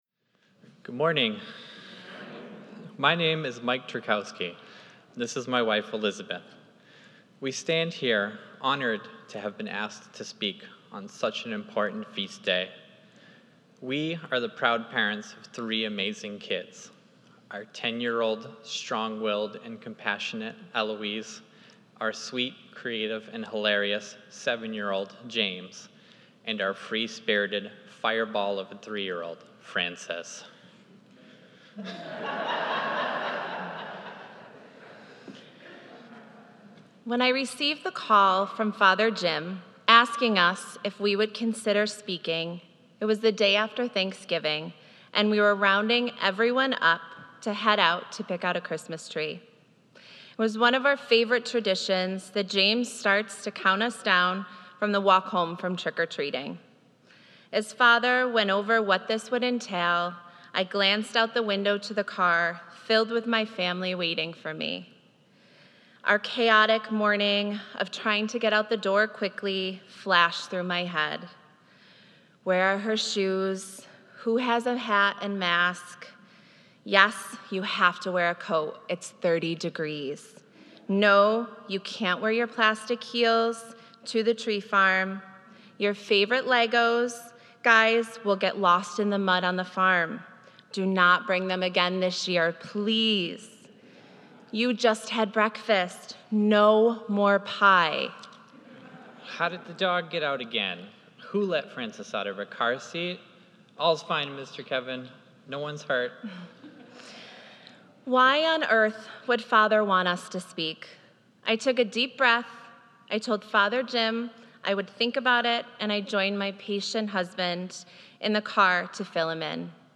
Holy Family Liturgy 2021